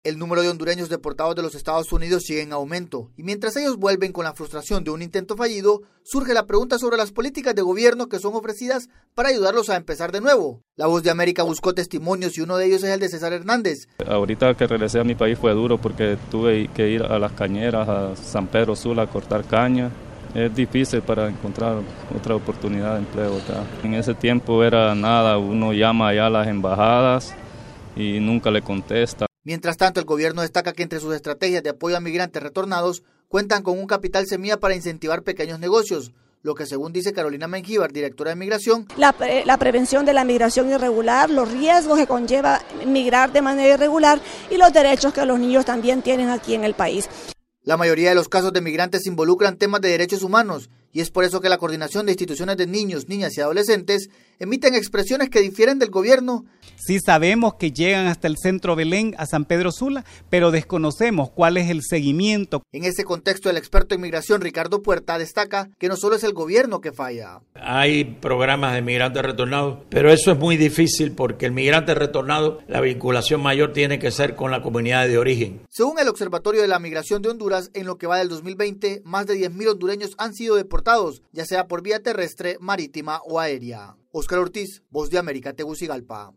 VOA: Informe de Honduras